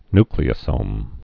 (nklē-ə-sōm, ny-)